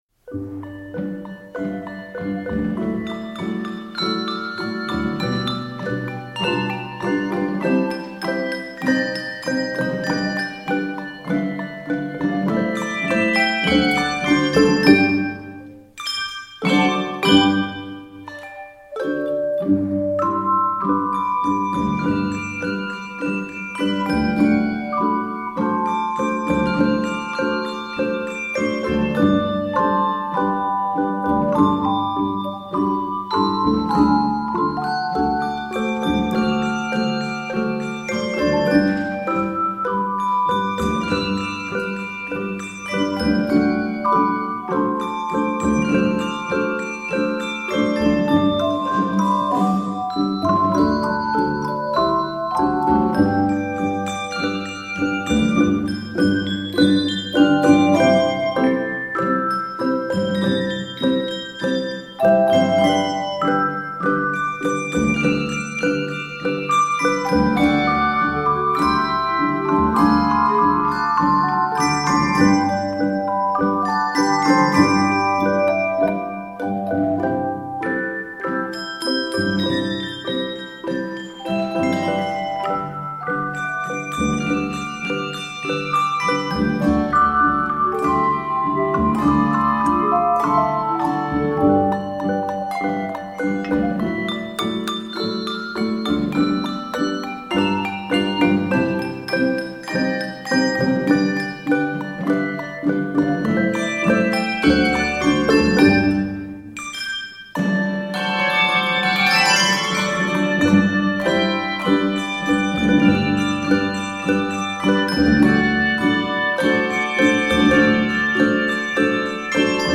handbells, handchimes, and optional snare drum